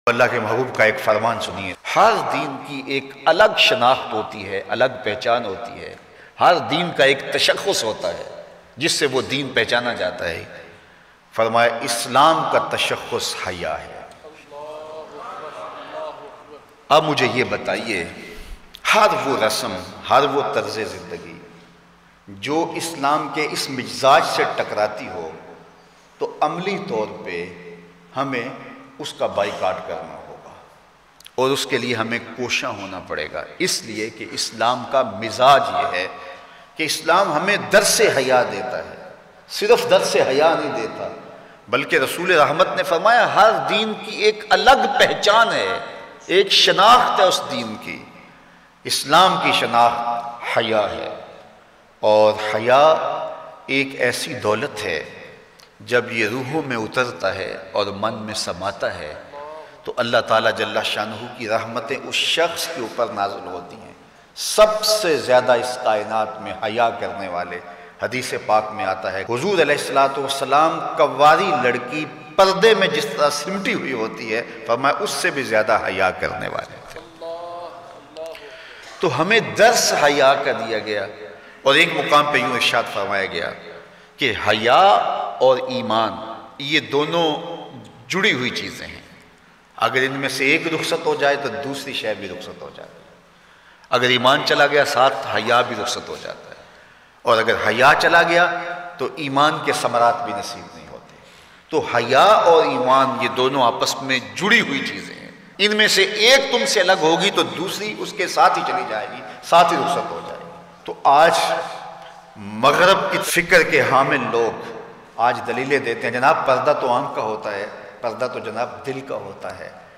Haya ka Culture Aam kro Bayan MP3 Download